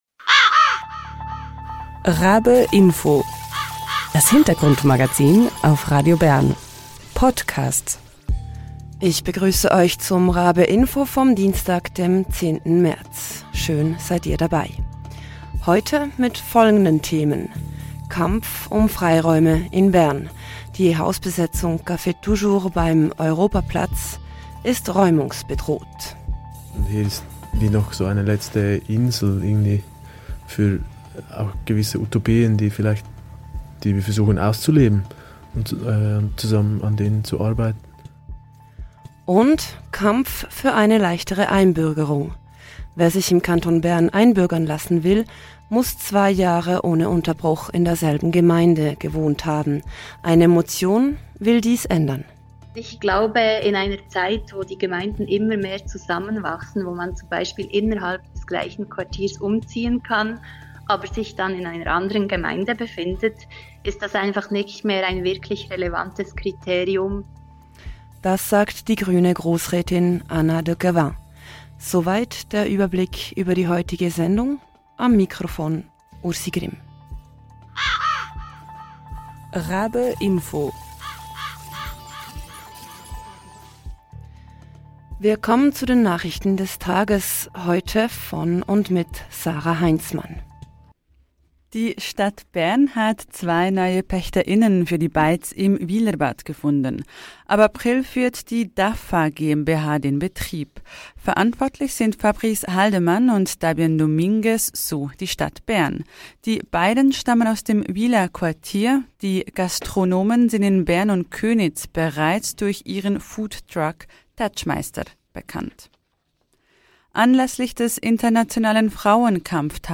Beschreibung vor 1 Jahr In der heutigen Sendung hören wir eine Reportage über das räumungsbedrohte Café Toujours, eine Hausbesetzung am Europaplatz, die seit 2016 existiert. Ausserdem hören wir im Interview von einem Vorstoss im kantonalen Parlament, der Hürden bei der Einbürgerung abbauen will. Aktuell ist es nämlich so, dass wer ein Gesuch auf Einbürgerung stellt, nicht nur während zwei Jahren im selben Kanton wohnen muss, sondern auch in derselben Gemeinde.